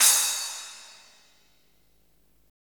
Index of /90_sSampleCDs/Roland - Rhythm Section/CYM_Cymbals 1/CYM_Cymbal menu
CYM SPLAS09L.wav